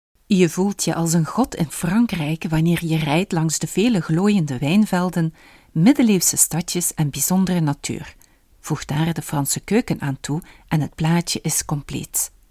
Vous pourrez compter sur un enregistrement impeccable de la voix néerlandaise flamande (belge), la voix française, la voix anglaise ou la voix espagnole grâce à l’équipement professionnel dont je dispose.
Ton Reportage / TV – Exemple voix off en Néerlandais (Flamand)